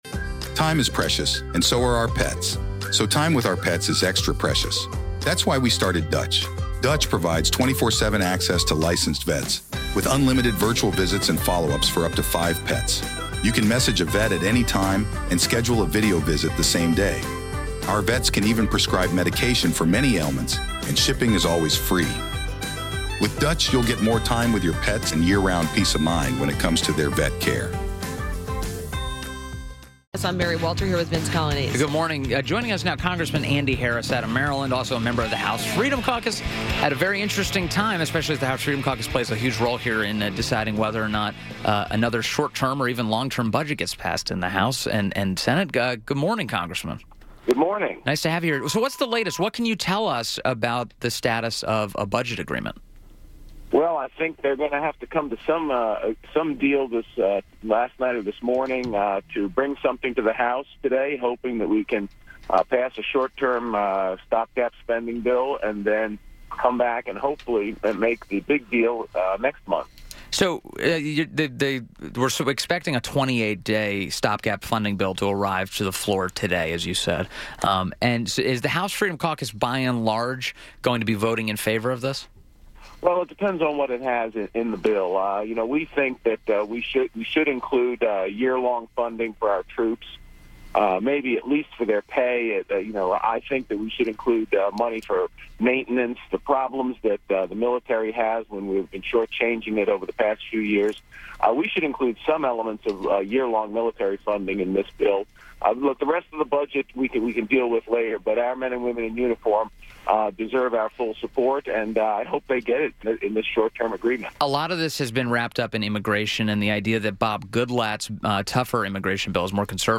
WMAL Interview - ANDY HARRIS - 01.18.18